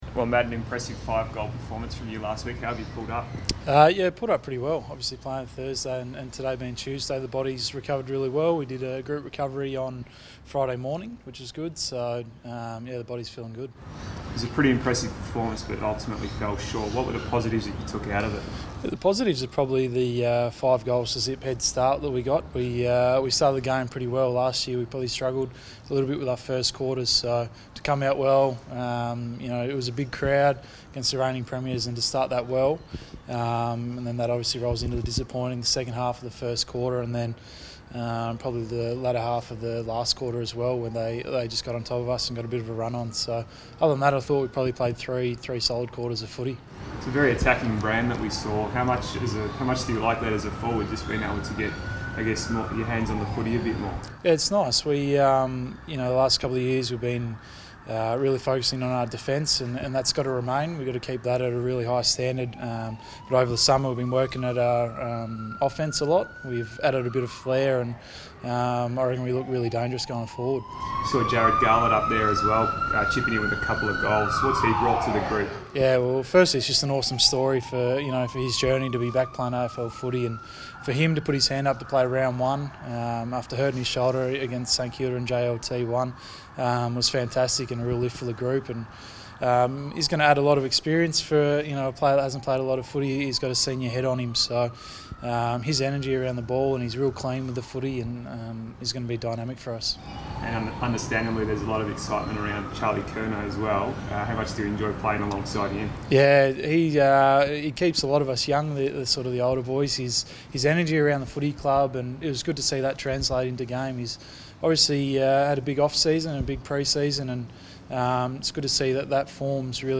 Matthew Wright press conference | March 27
Carlton forward Matthew Wright speaks to the media at Ikon Park ahead of the Blues' Round 2 clash with Gold Coast.